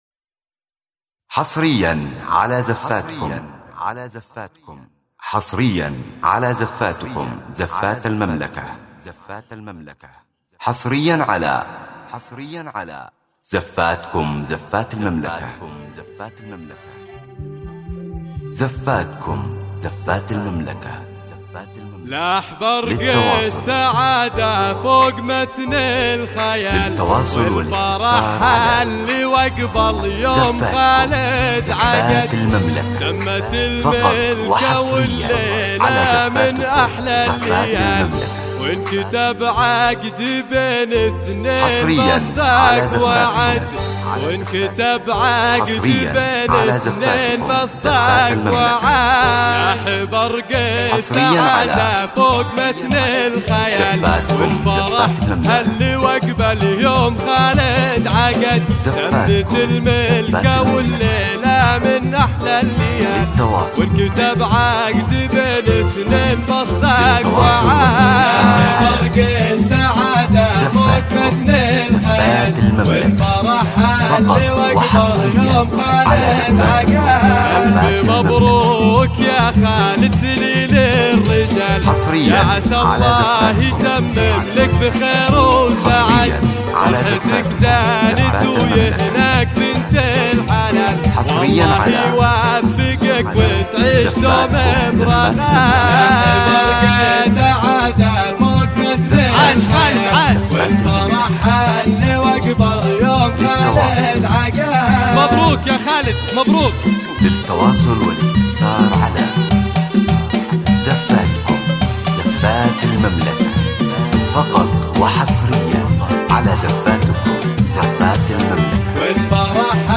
مثالية لحفلات الملكة وبتوزيع موسيقي راقٍ
زفة موسيقية